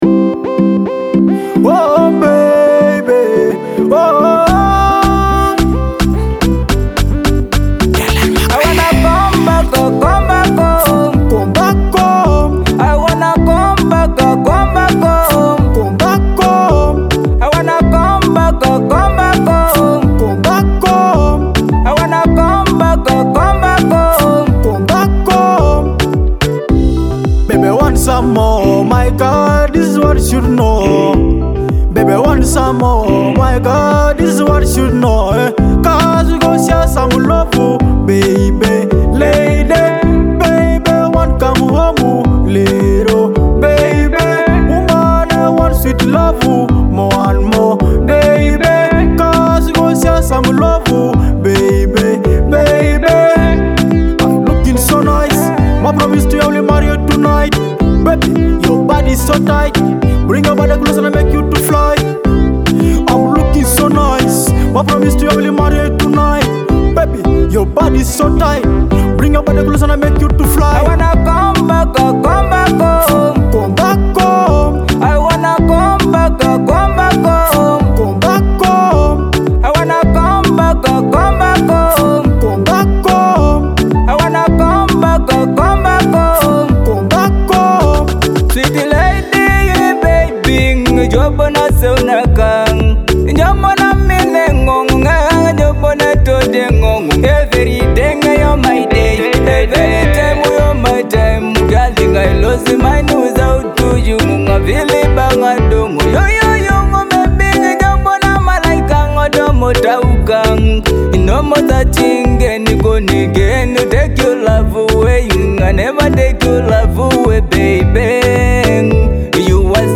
With its catchy hooks and vibrant beat,